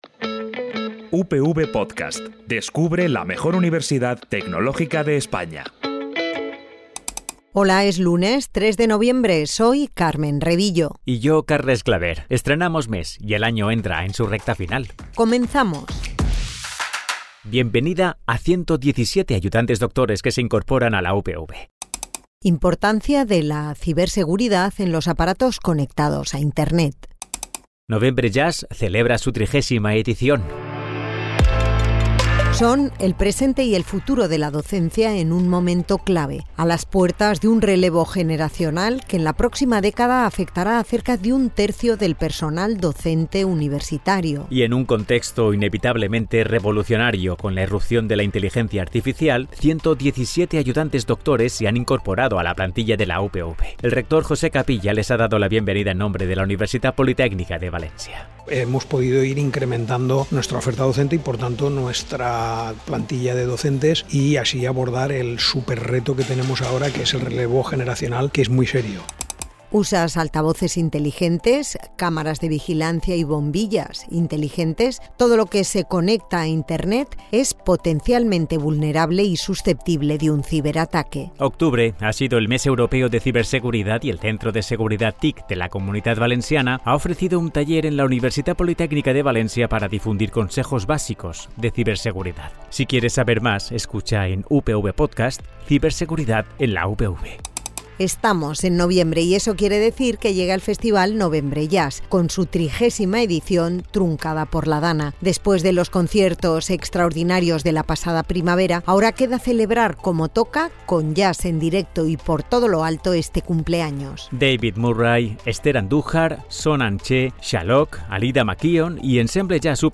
És la versió sonora del butlletí informatiu per a informar-te del que passa a la Universitat Politècnica de València.